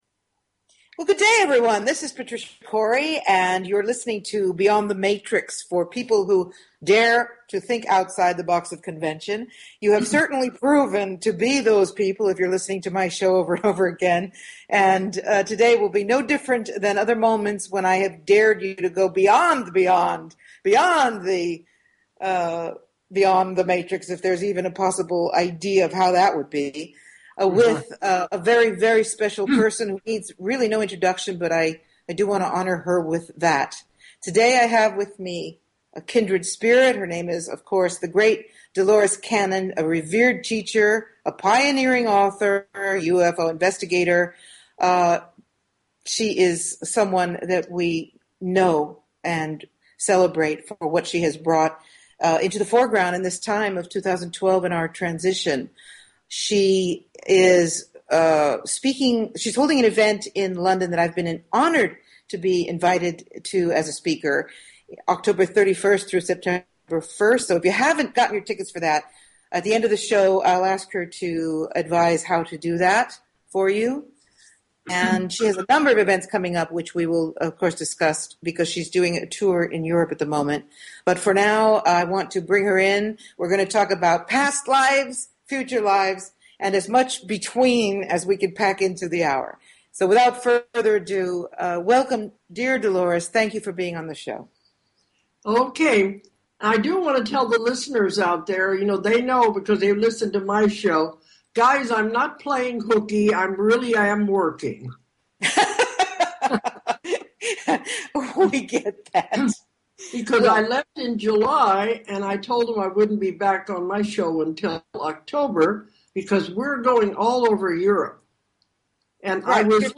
Talk Show Episode, Audio Podcast, Beyond_The_Matrix and Courtesy of BBS Radio on , show guests , about , categorized as
SHORT DESCRIPTION. This revealing interview features world-famous teacher, pioneering author, and UFO investigator Dolores Cannon, who discusses past life recall, the quantum healing technique, and more.